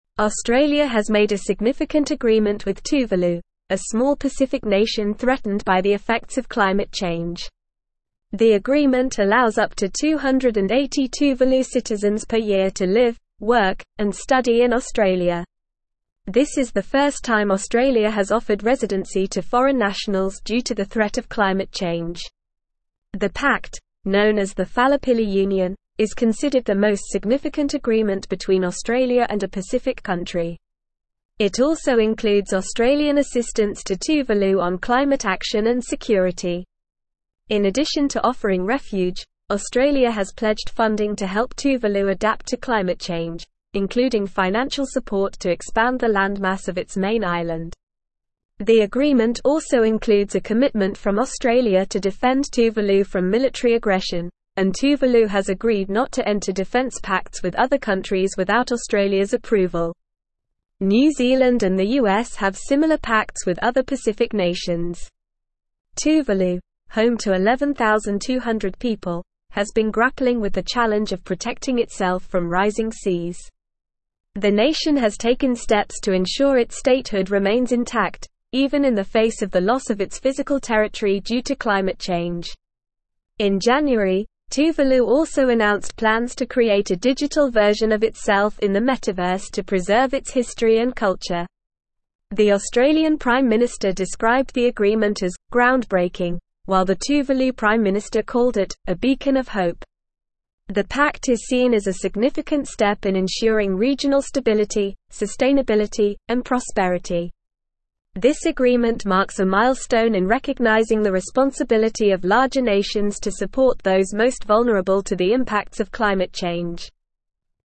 Normal
English-Newsroom-Advanced-NORMAL-Reading-Australias-Historic-Pact-Refuge-for-Tuvaluans-from-Climate-Change.mp3